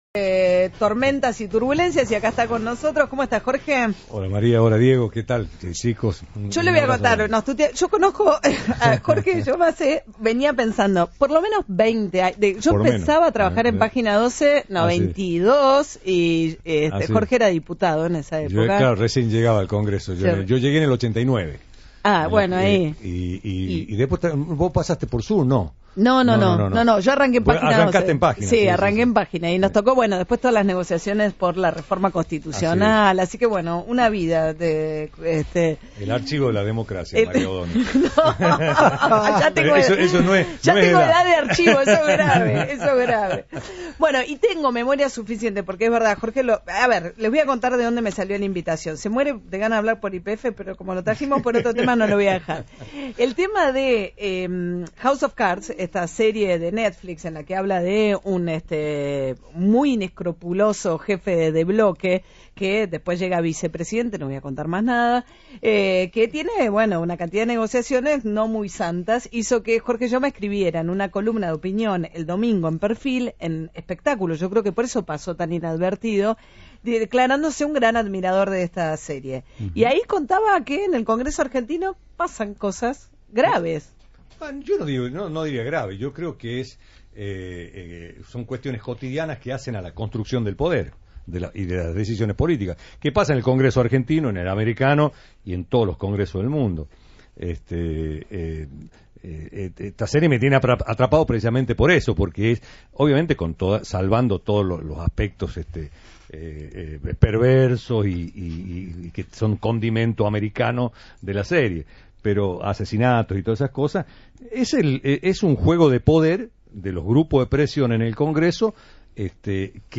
‘Cuestiones cotidianas que hacen a la construcción del poder’. De esta forma, Jorge Yoma describió en el piso de Radio Continental cuestiones muy banales del día a día como concurrente activo del Congreso.